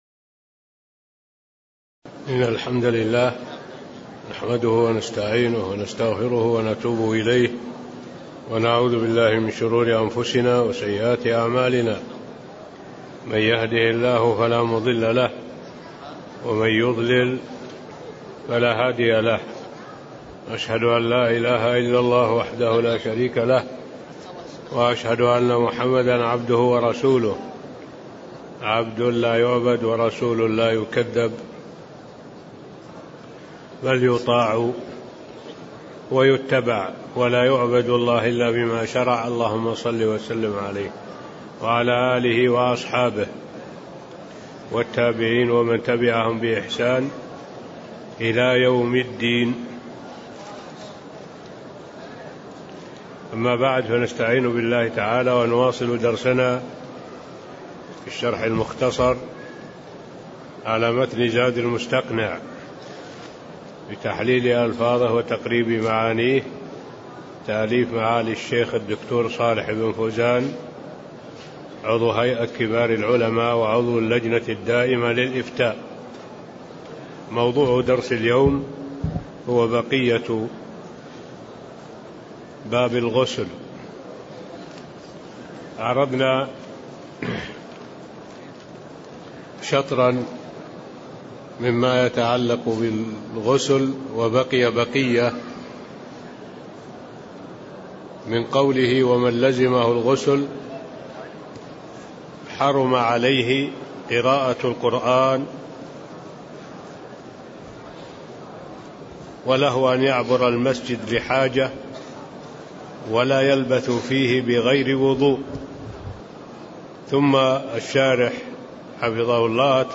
تاريخ النشر ٦ ربيع الثاني ١٤٣٤ هـ المكان: المسجد النبوي الشيخ: معالي الشيخ الدكتور صالح بن عبد الله العبود معالي الشيخ الدكتور صالح بن عبد الله العبود باب الغسل (09)